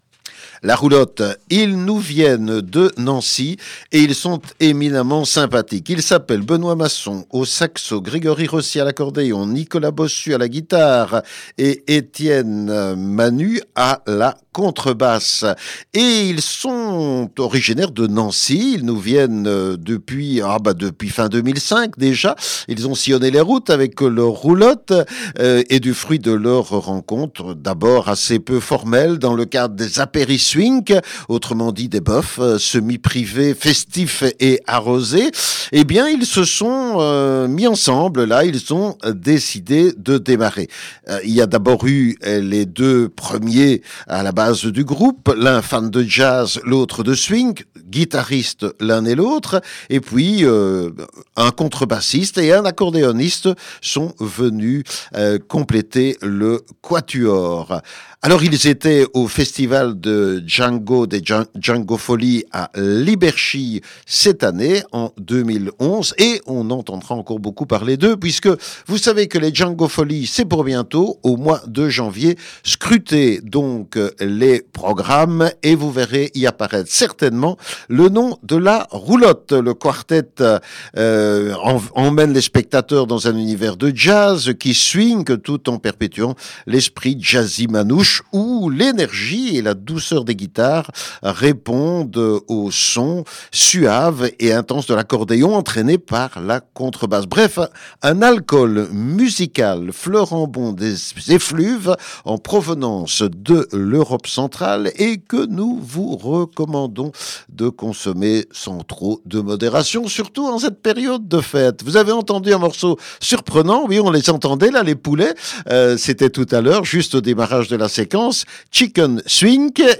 c’est donc un quartet formé de deux guitaristes
le swing manouche.